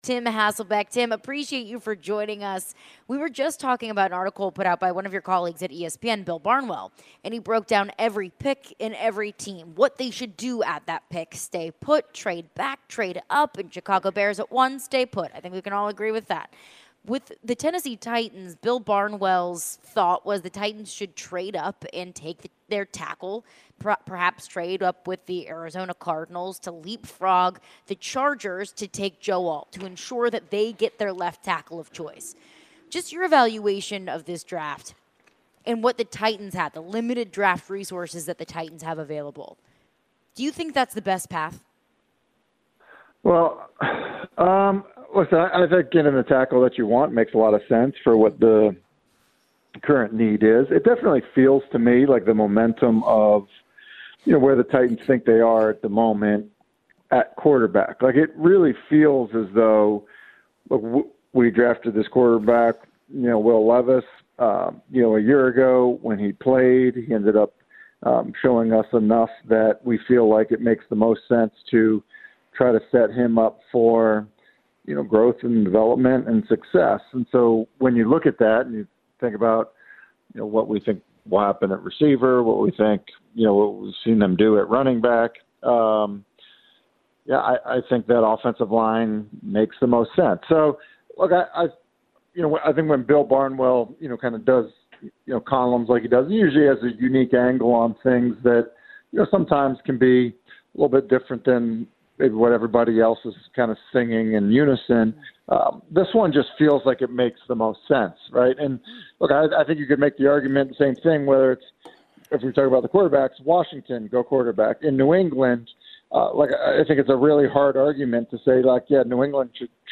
Tim Hasselbeck ESPN NFL analyst joined the show discussing all news around the NFL and what the Titans should possibly do in this year‘s draft. Tim also mentioned the recent comments made about Malik Nabers.